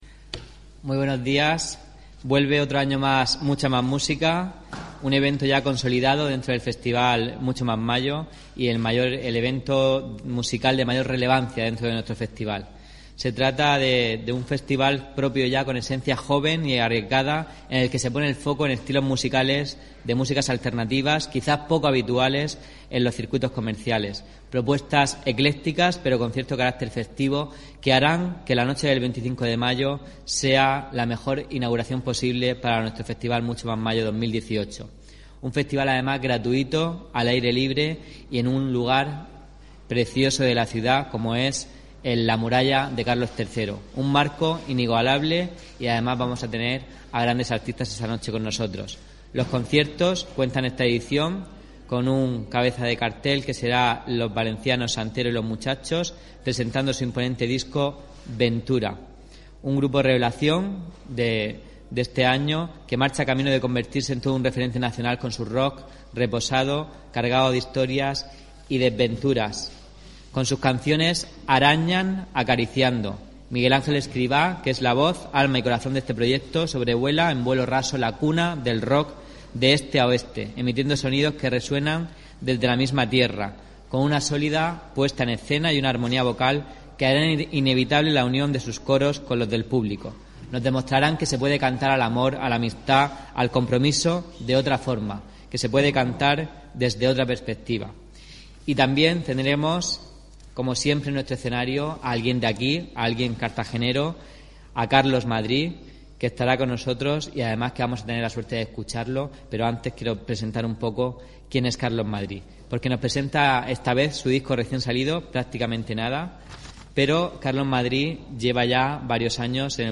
Audio: Presentaci�n Mucha M�s M�sica (MP3 - 4,83 MB)